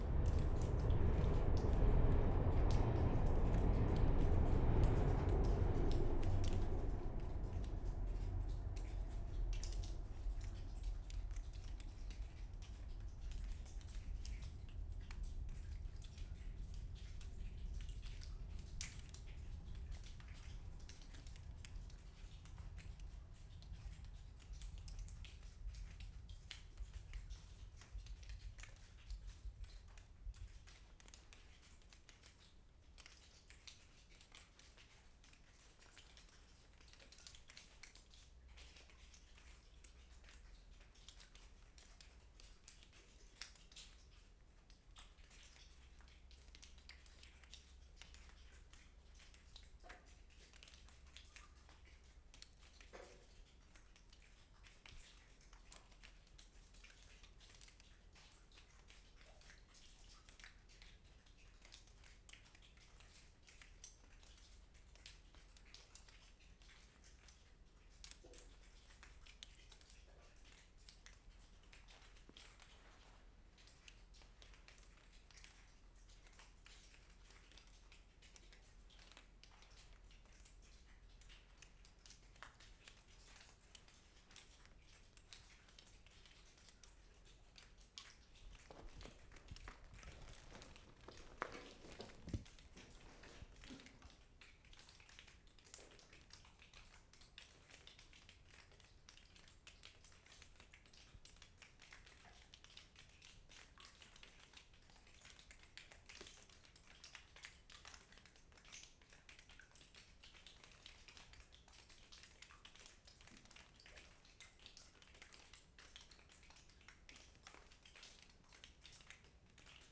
Water dripping in tunnel.
mark * nice clear drips [0:12]
4. Disappearing train in neighbouring tunnel can be heard at beginning of this take.